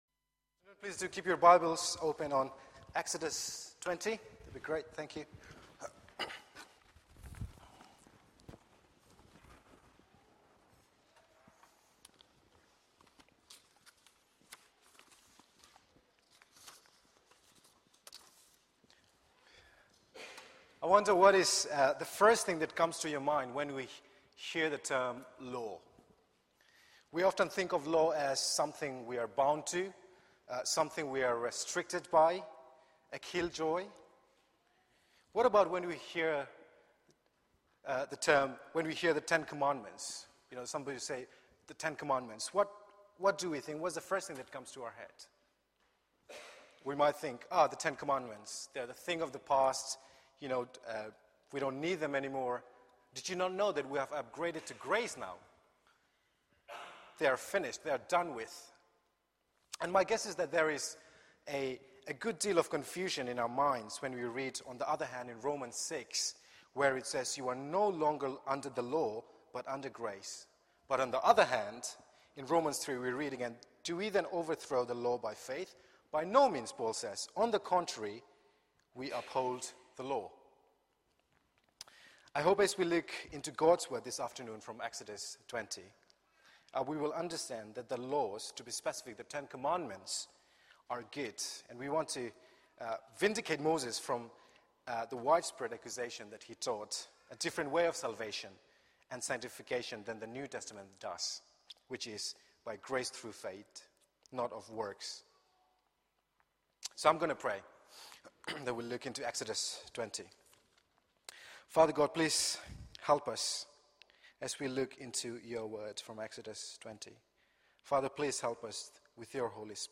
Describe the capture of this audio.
Media for 4pm Service on Sun 30th Jun 2013 16:00 Speaker